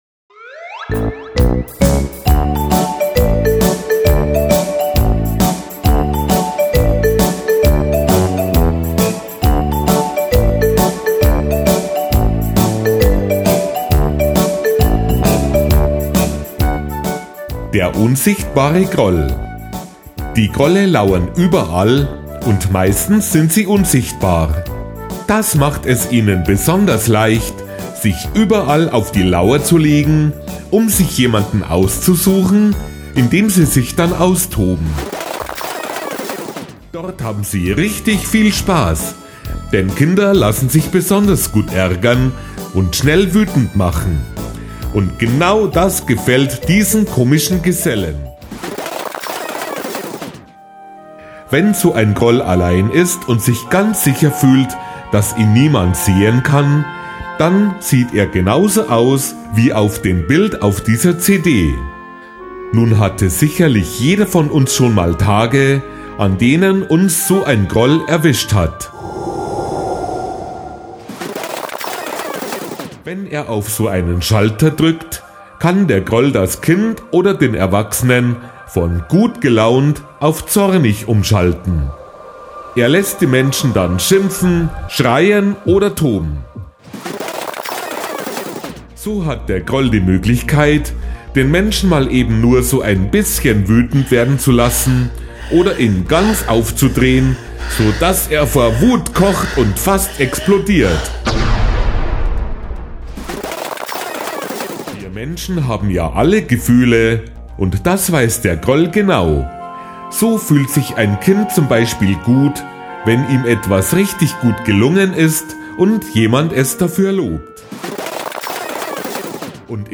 Kinder-Hörbuch Kurzgeschichte „Der unsichtbare Groll“ zum Download als mp3
• Hörtyp:  Hörbuch als Download